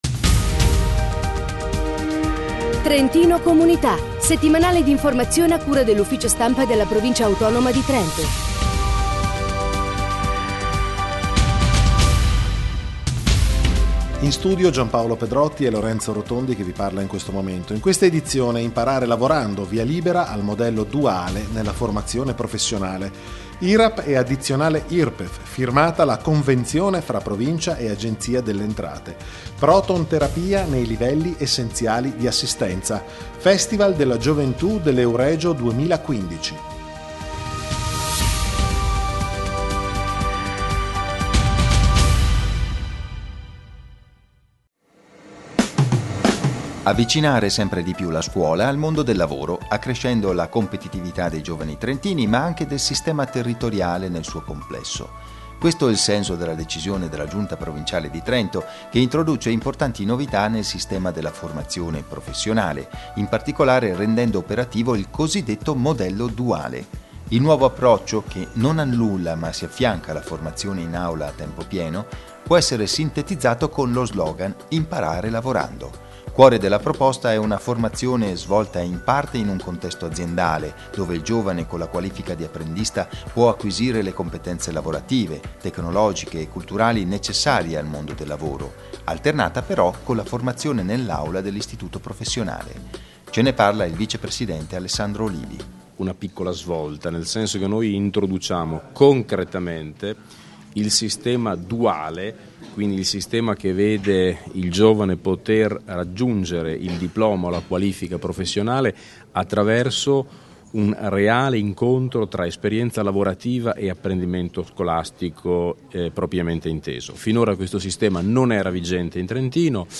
Notiziario Cultura Economia, imprese e attività produttive